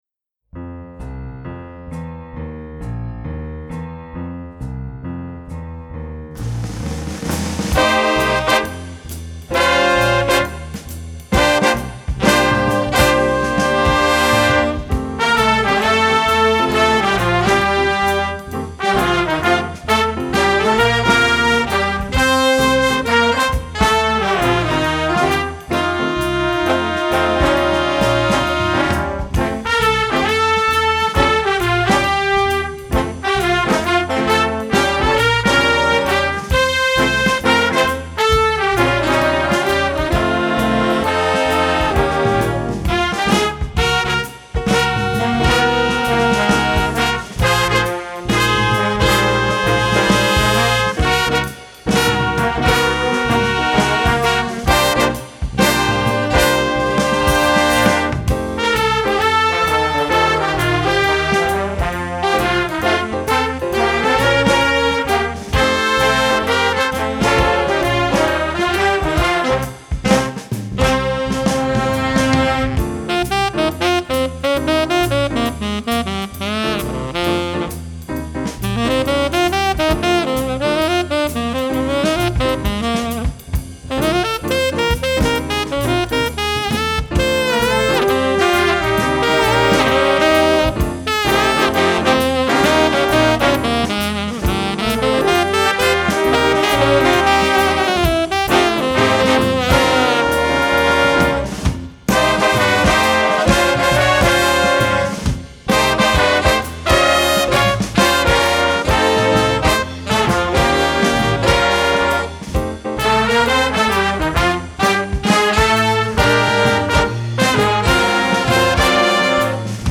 Voicing: Flex Jazz